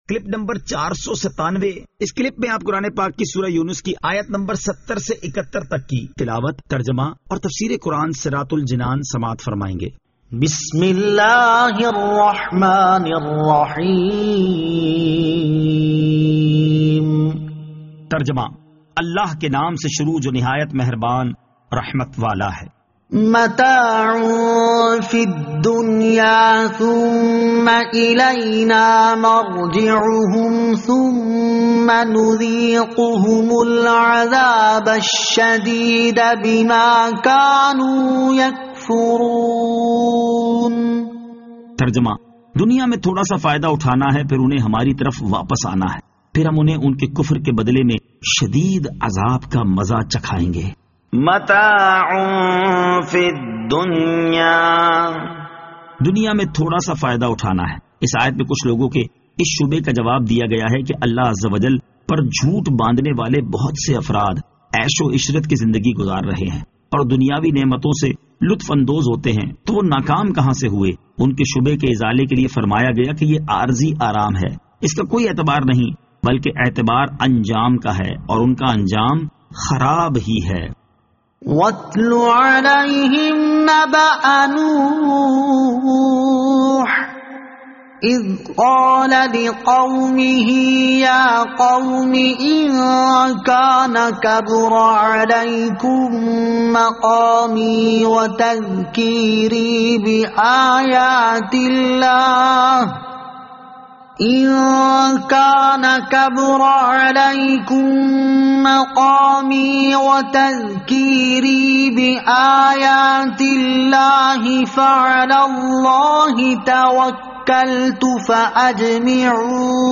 Surah Yunus Ayat 70 To 71 Tilawat , Tarjama , Tafseer
2021 MP3 MP4 MP4 Share سُوَّرۃُ یُونُس آیت 70 تا 71 تلاوت ، ترجمہ ، تفسیر ۔